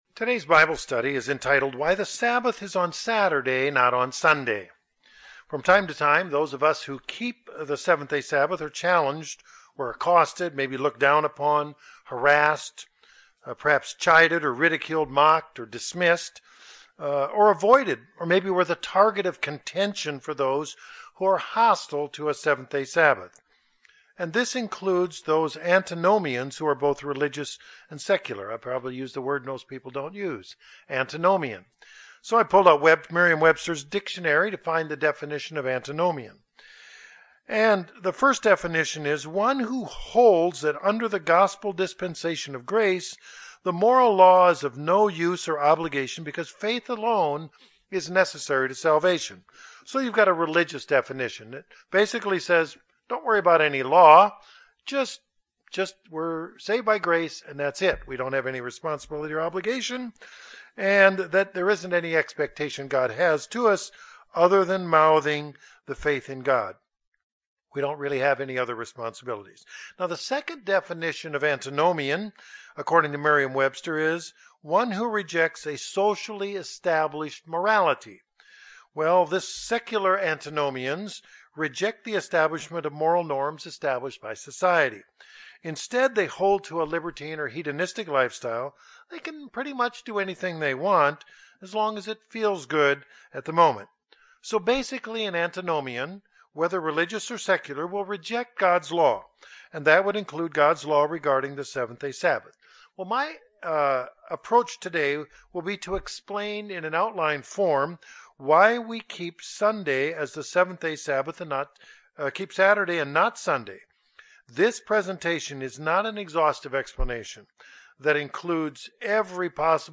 Bible Study: Why the Sabbath is on Saturday and NOT on Sunday 5-7-2016 Cave Springs, AR